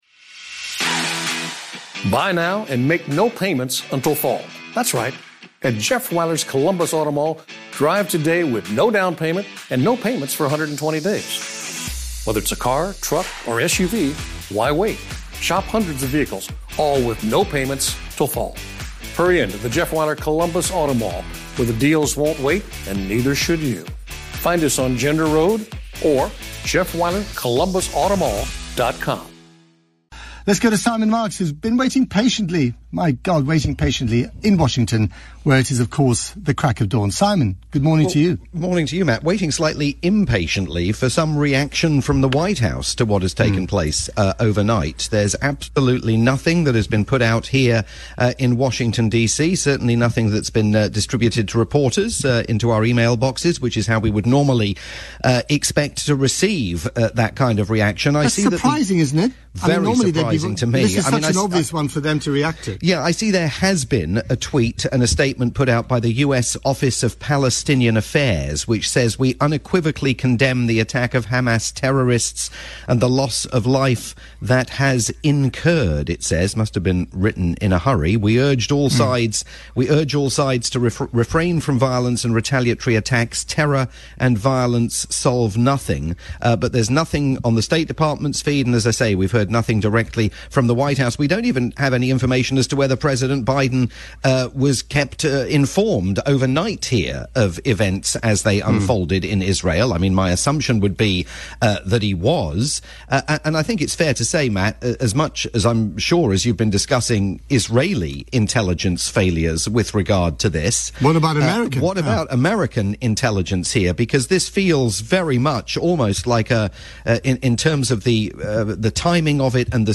live update for Matt Frei's Saturday morning programme on the UK's LBC.